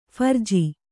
♪ pharji